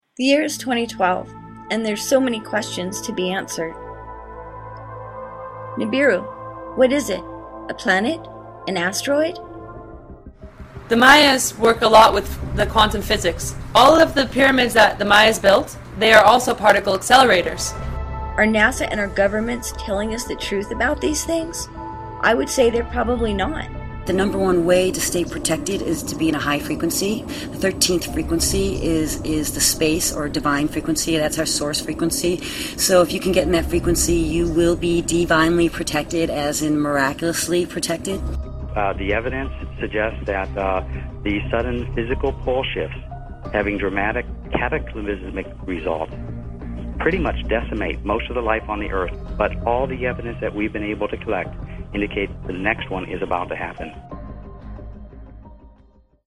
An audio-collage I made for The Pod Delusion featuring a selection of nonsense-talking rapture cranks.